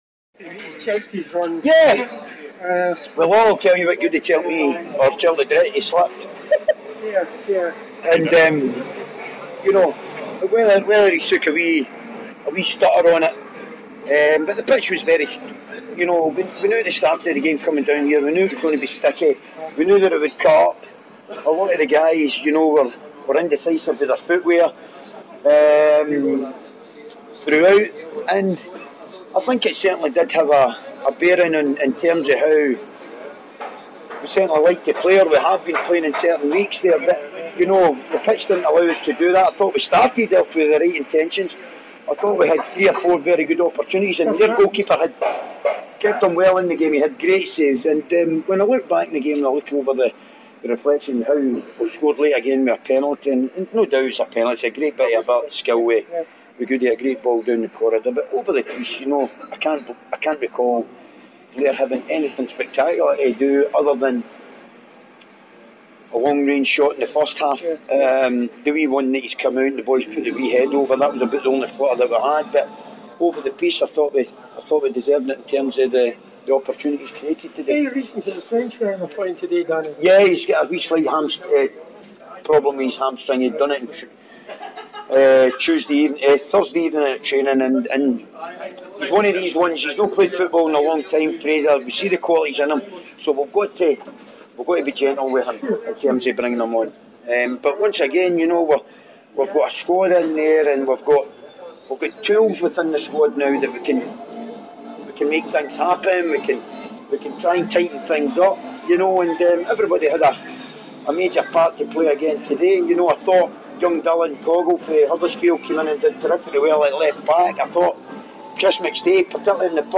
press conference after the Ladbrokes League 2 match.